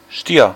Ääntäminen
US : IPA : [ˈtɔː.rəs]